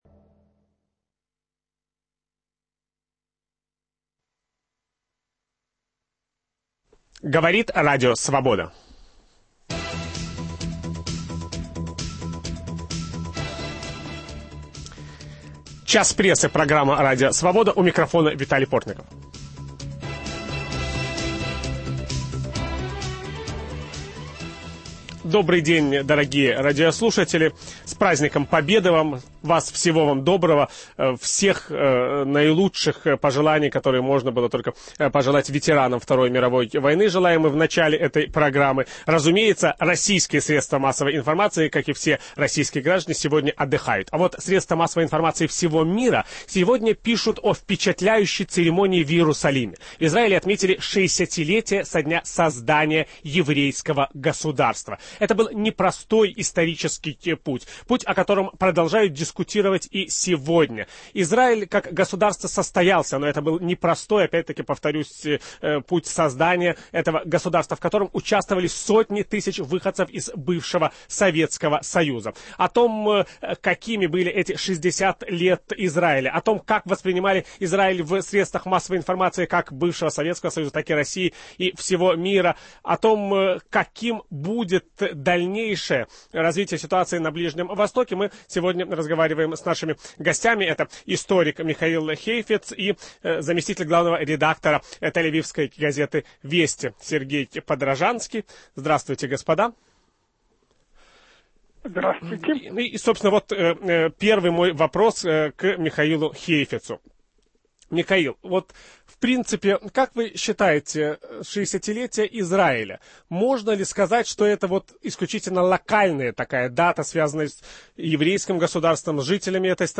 ведущий программы Виталий Портников беседует с историком